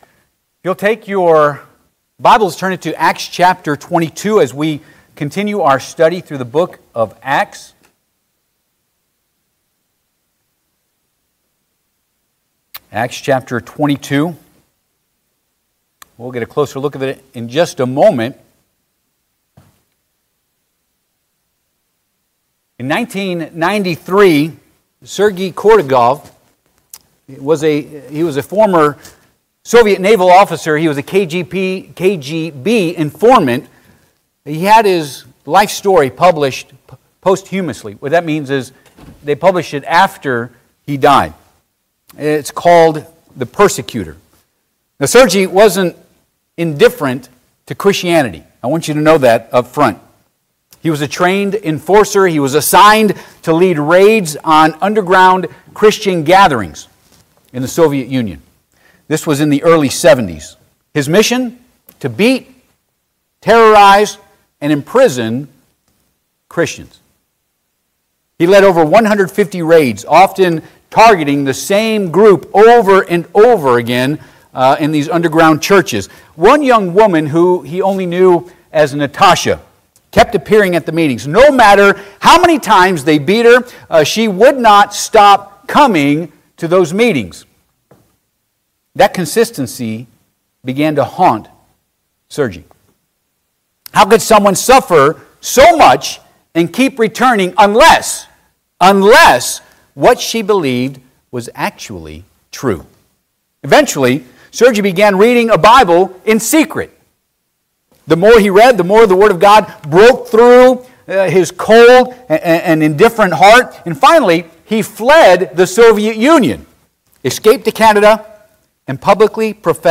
Acts Passage: Acts 22 Service Type: Sunday AM « The Period & Work of the Judges